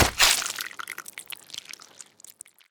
claw2.wav